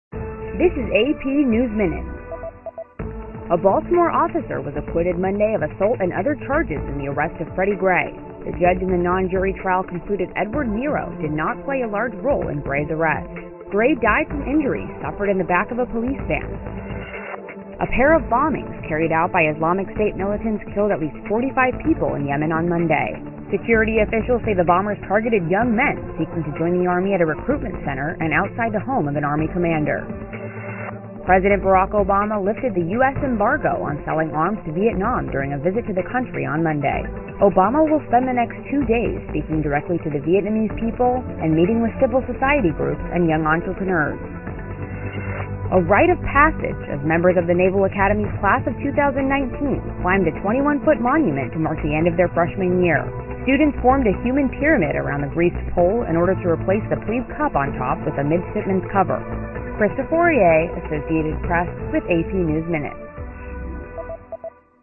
News